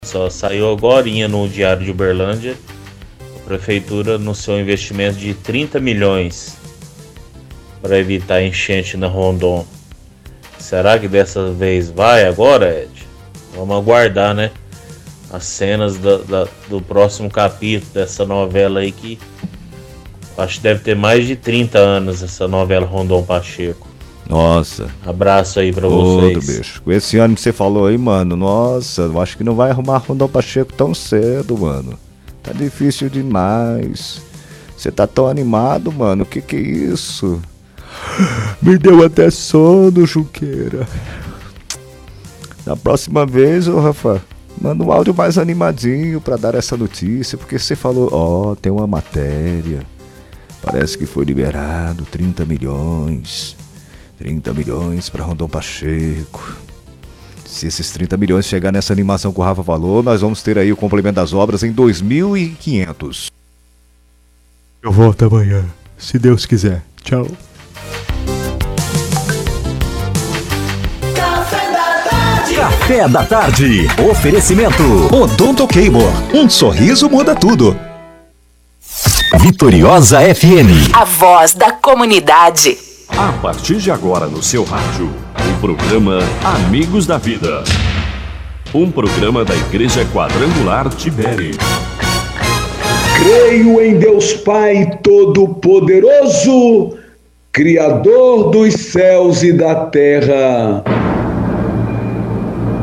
Ligação Ouvinte – Investimento Rondon Pacheco